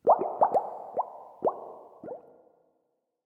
bubble_column
upwards_ambient2.ogg